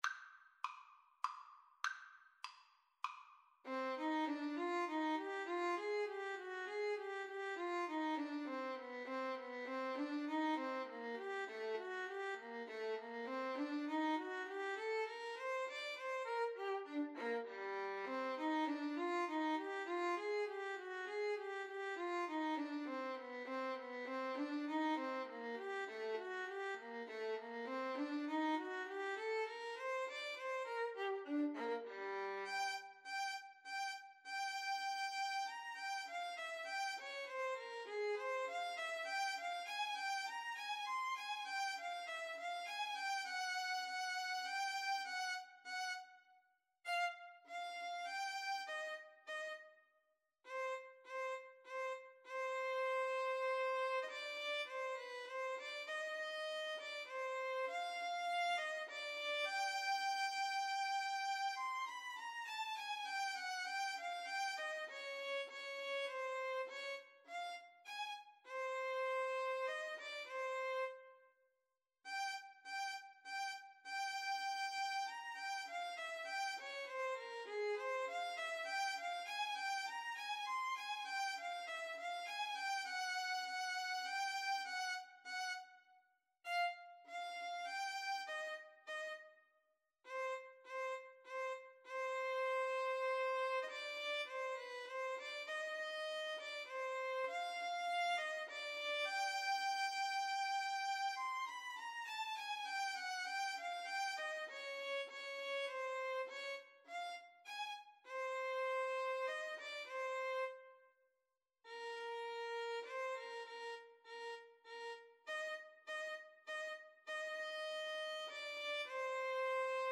Free Sheet music for Violin Duet
Violin 1Violin 2
3/4 (View more 3/4 Music)
C minor (Sounding Pitch) (View more C minor Music for Violin Duet )
Moderato
Violin Duet  (View more Intermediate Violin Duet Music)
Classical (View more Classical Violin Duet Music)